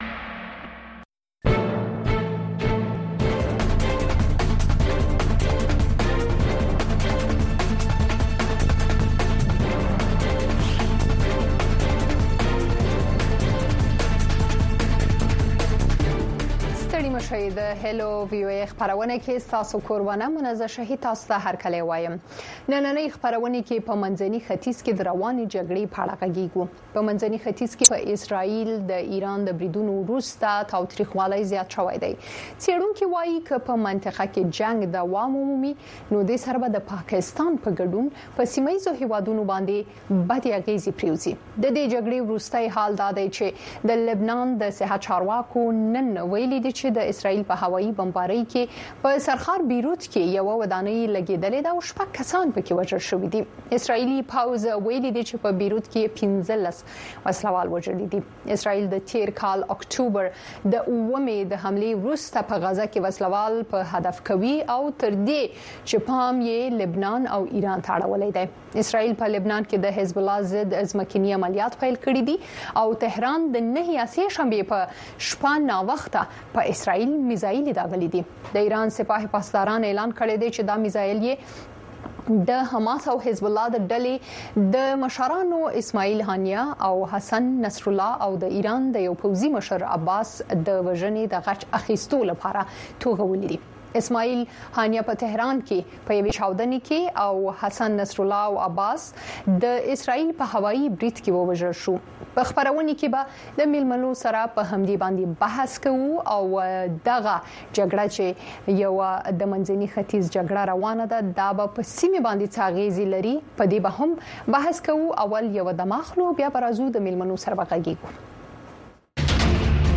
دې یو ساعته پروگرام کې تاسو خبرونه او د هغې وروسته، په یو شمېر نړیوالو او سیمه ایزو موضوگانو د میلمنو نه پوښتنې کولی شۍ.